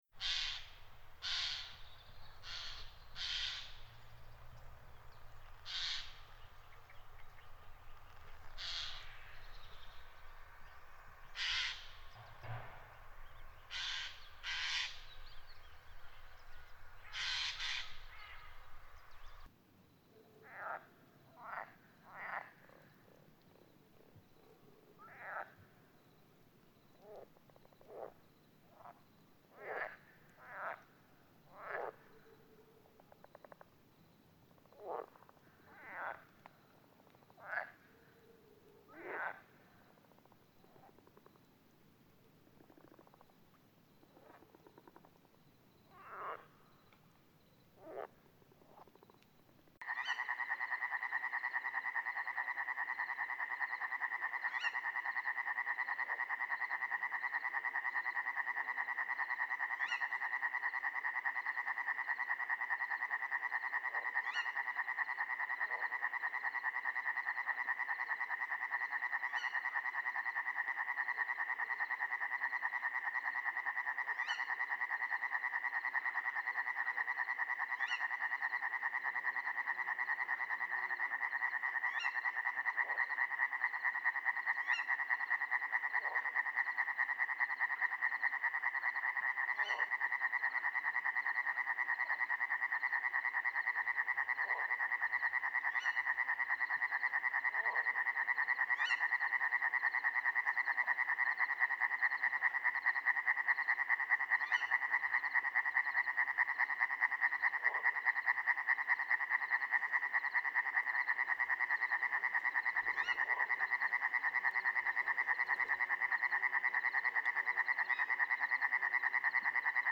Il bosco
Bosco e ruscello sull'Appennino modenesePer ricordare ai bambini l'atmosfera del bosco, colma di voci e di silenzi, proponiamo loro l'ascolto di una registrazione effettuata nei pressi di Sassomassiccio1, sull'Appennino modenese.
Pur essendo ben riconoscibile l'ambiente nel suo complesso, risulta ai bambini meno immediato isolare le figure "uditive" dallo sfondo sonoro.
…era una rana che faceva "gra gra" e poi c'erano i grilli
…ci sono gli uccellini e uno fa tanto rumore
1 Stagno di Sassomassiccio, giugno, tratto da: Popoli T., Riserva naturale orientata di Sassoguidano, collana Naturalmente Sonori: suoni dalle aree protette della provincia di Modena, Modena: Provincia, 2002, CD.
Contiene una selezione di suoni registrati tra il 1997 e il 2002 nella Riserva naturale orientata di Sassoguidano.